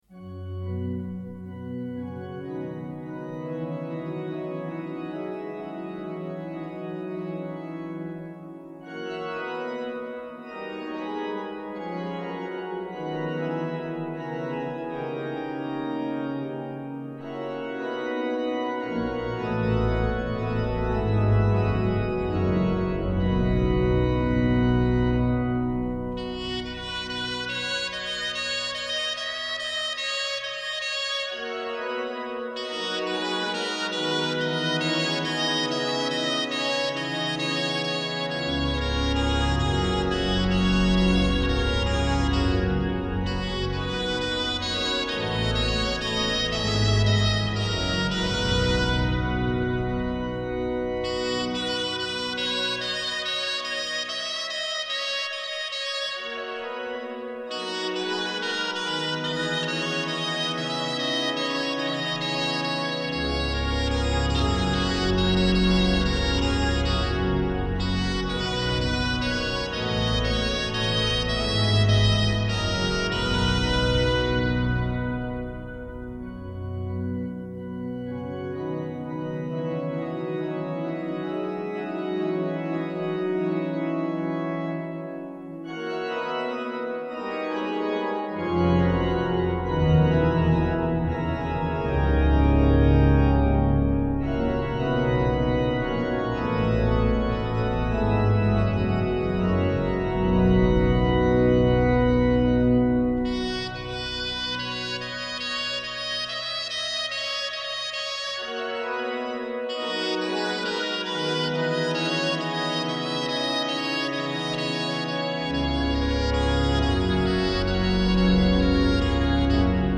S.I. Catedral Metropolitana de Valladolid
GRAN CONCIERTO DE NAVIDAD 2006
Órgano con Dulzaina castellana y con Flauta de llaves
Organ with Castilian Dulzaina or Key Flute